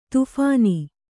♪ tuphāni